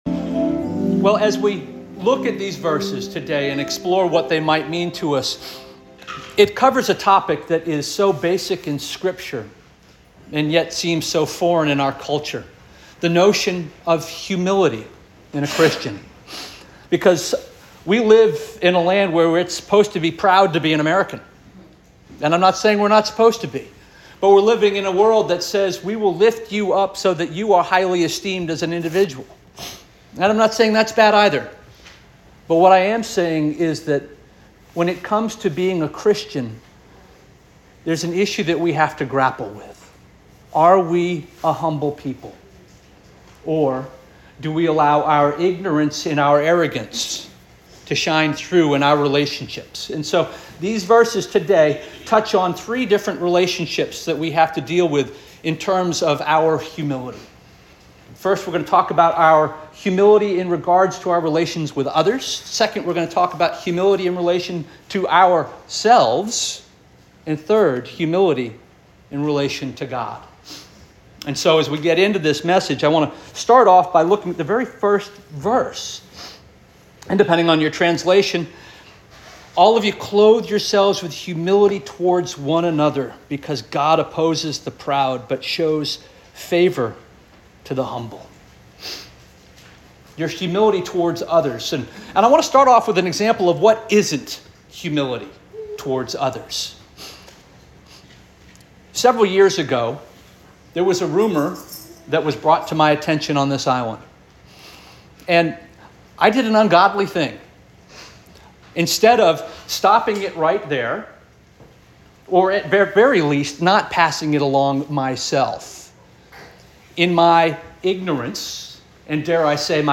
March 30 2025 Sermon